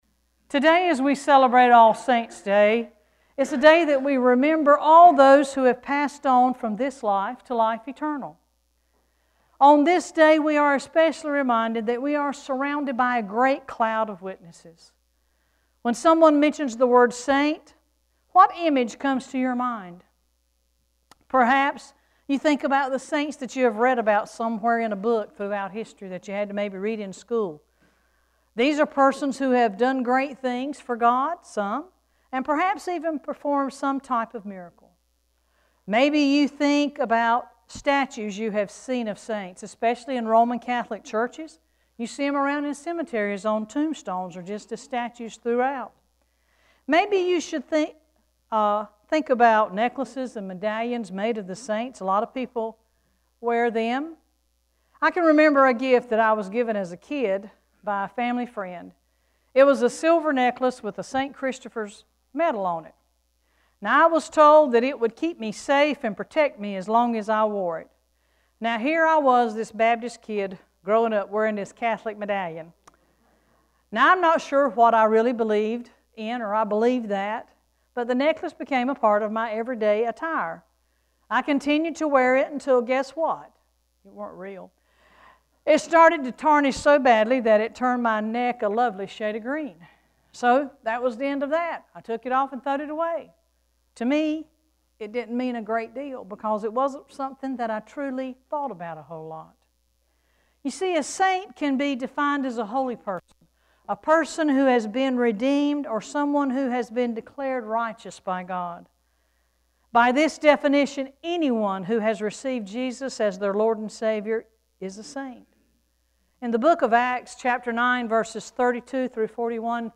11-2-sermon.mp3